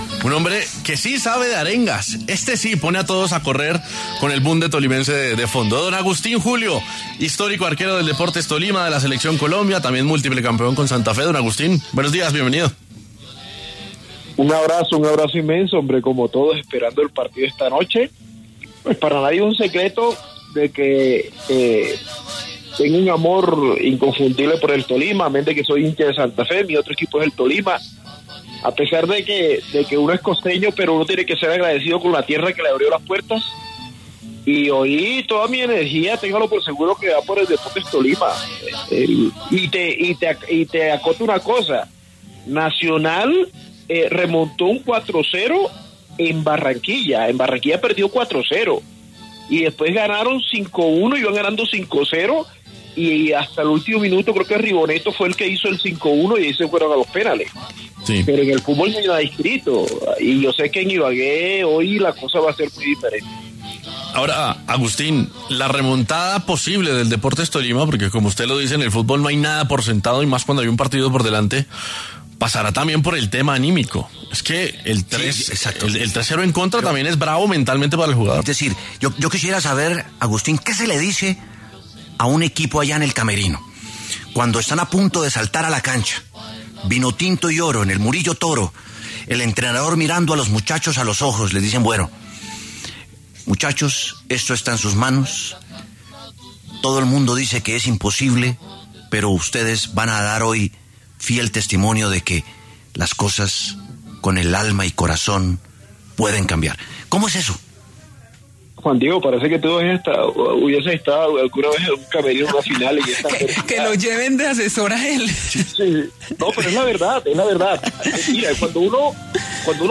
Agustín Julio, histórico arquero del Deportes Tolima e Independiente Santa Fe, dialogó con La W acerca del partido de vuelta de la gran final del Fútbol Profesional Colombiano (FPC) en la que el equipo ‘Vinotinto y Oro’ buscará remontar el marcador adverso de 0-3 ante el Junior de Barranquilla.